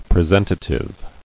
[pre·sent·a·tive]